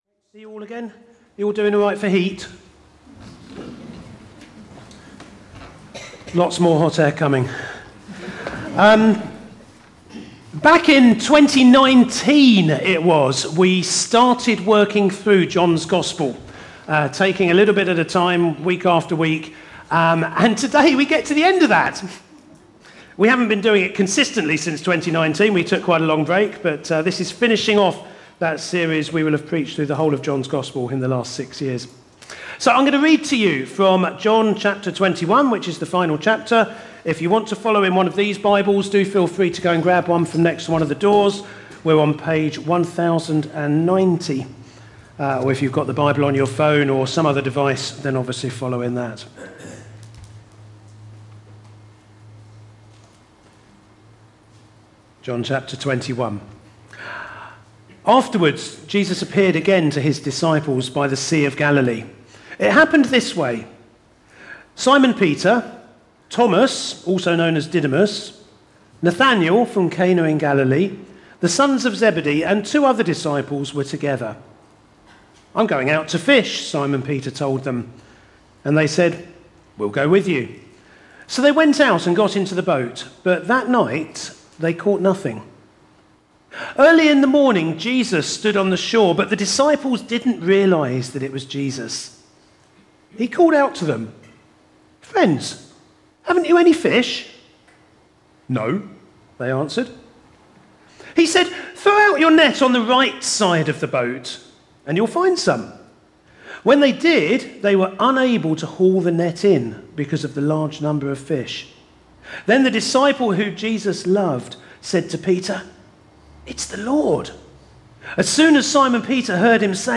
Show or hear video or audio of talks or sermons from the Thornhill Baptist Church archives.